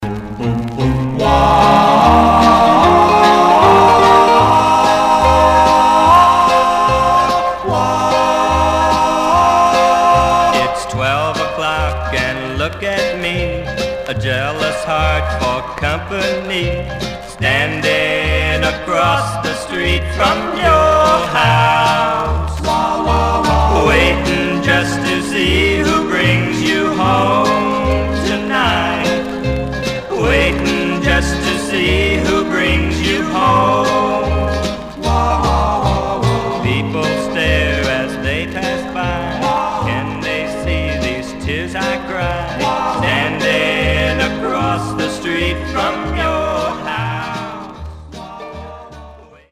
Some surface noise/wear Stereo/mono Mono
Time 1016 Categories: 45s, Teen (ie.